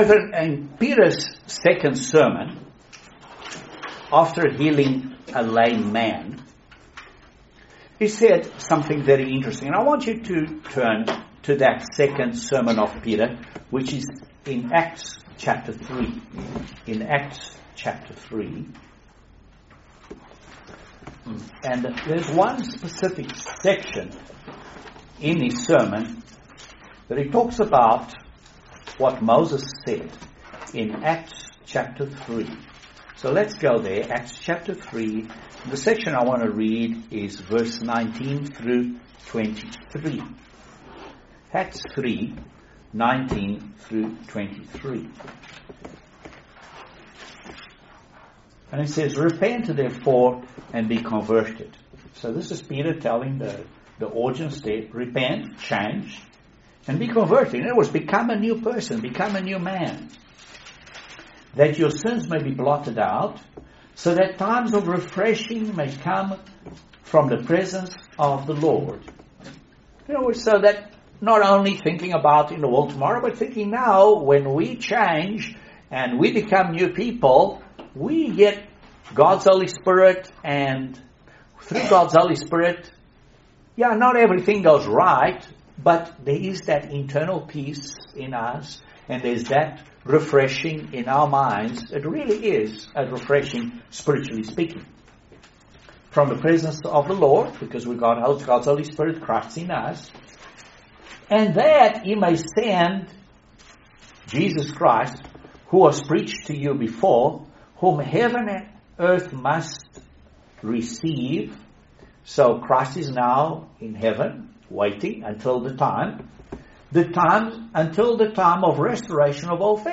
Excellent sermon about how to identify False Prophets and False Teachers. They appear sincere , but are they teaching the truth?